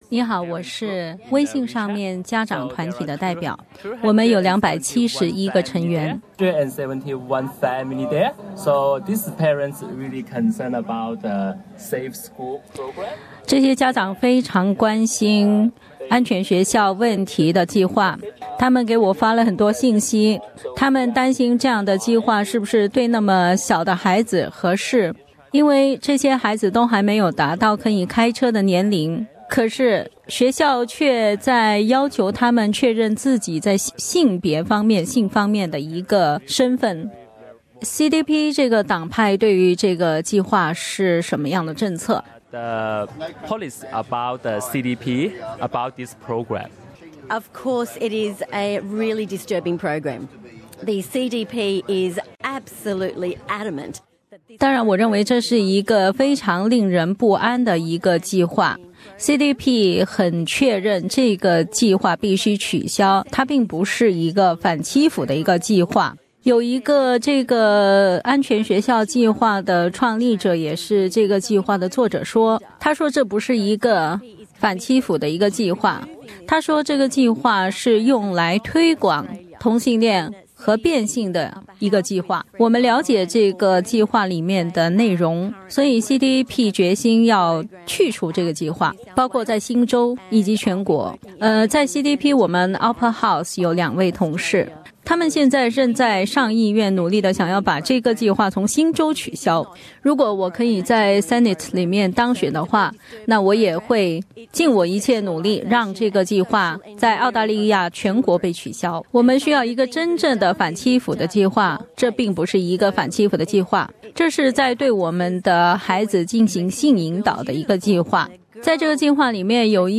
SBS中文节目大选特别户外节目于昨天上午11点至下午2点在悉尼Chatswood举行。SBS电台普通话团队在现场对候选人和社区民众进行了采访。